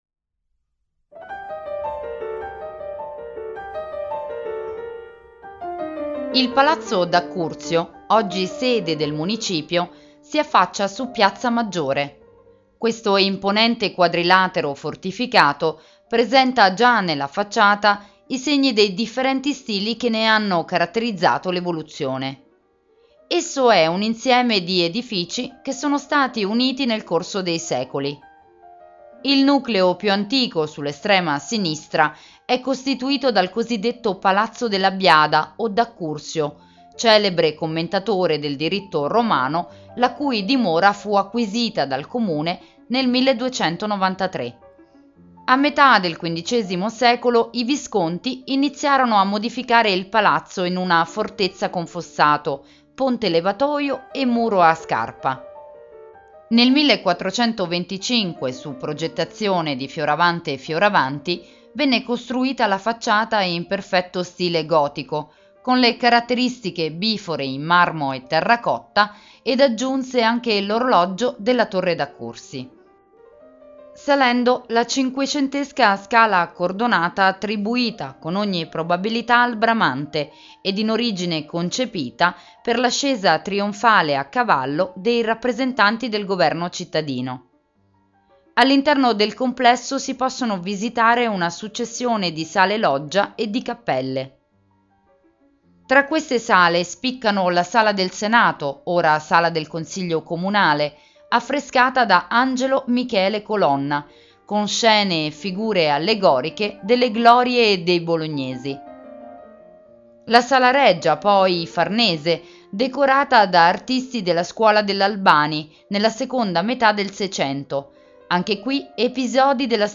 Audioguida Bologna - Palazzo D'Accursio - Audiocittà